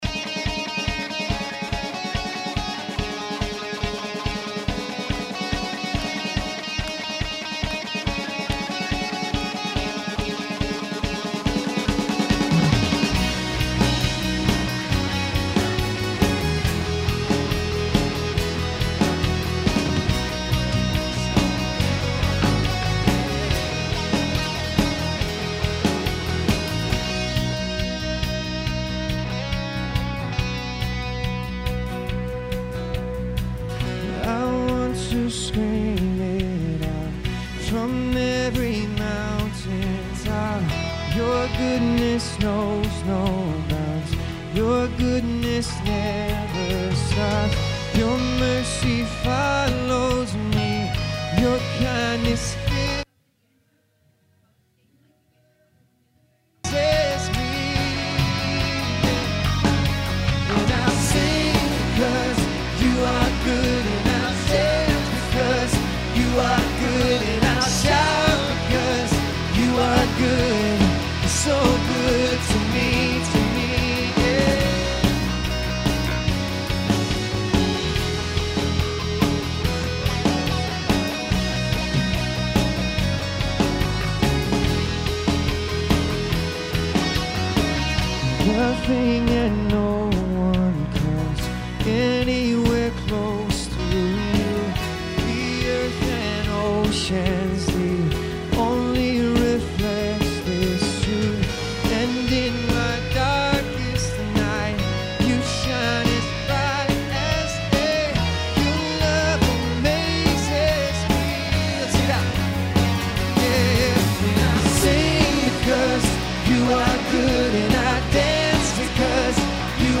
Watch our 2016 Thanksgiving service: Heart of Celebration.